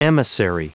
Prononciation du mot emissary en anglais (fichier audio)
Prononciation du mot : emissary